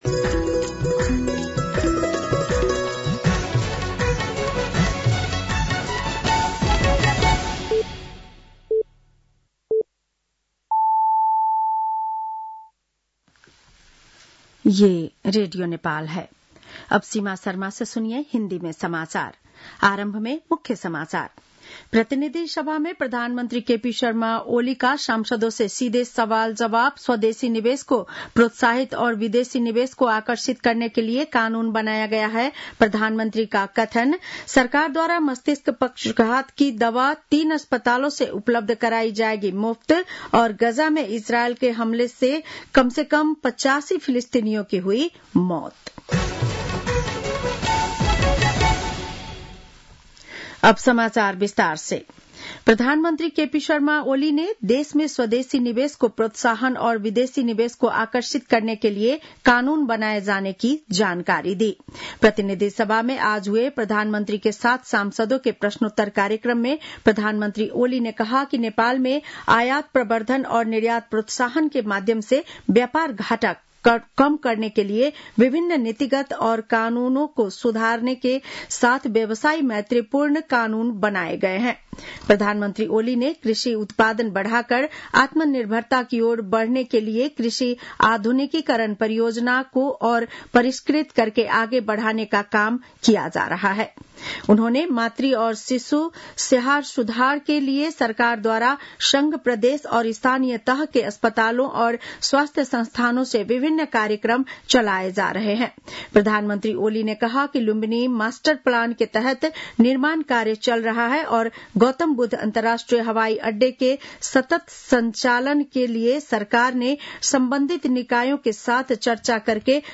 बेलुकी १० बजेको हिन्दी समाचार : ७ चैत , २०८१